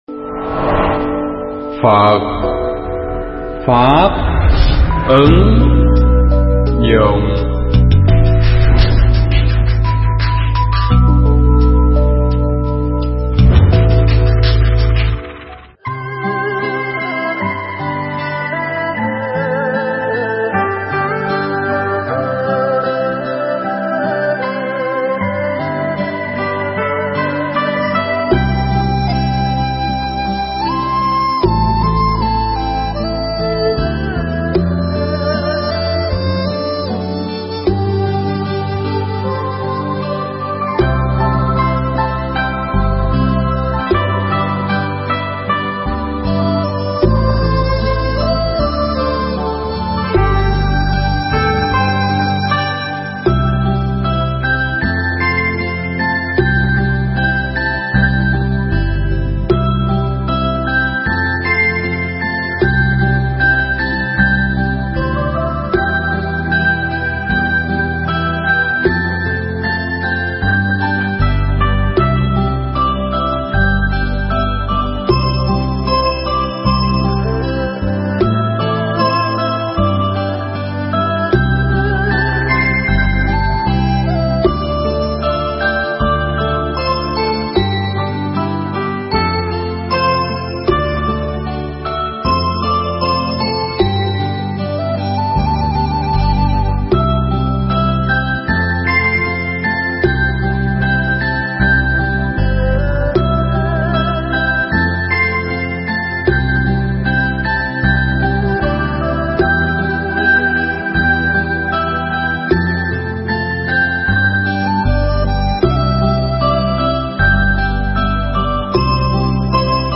Pháp thoại
trong khóa tu Một Ngày An lạc lần thứ 42 tại Tu Viện Tường Vân